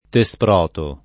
[ te S pr 0 to ]